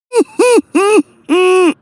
Goofy Ahh Homemade Laugh Sound Button: Unblocked Meme Soundboard